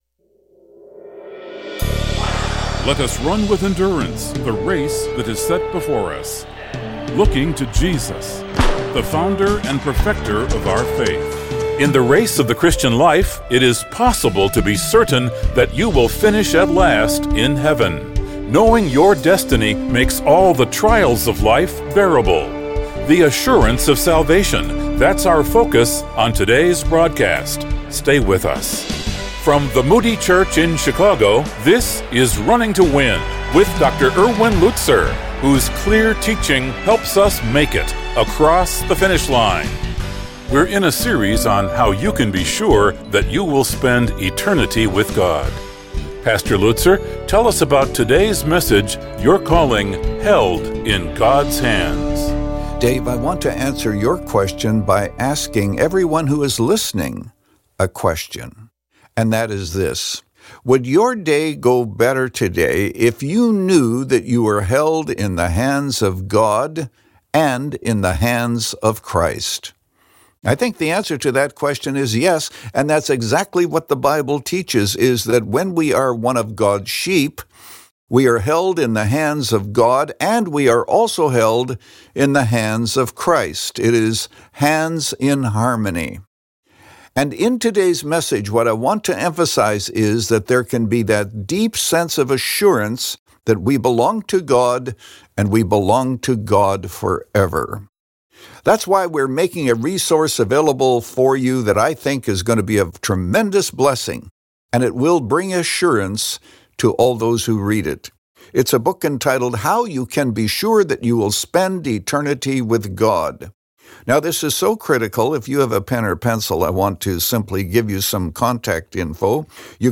In this message from Romans 8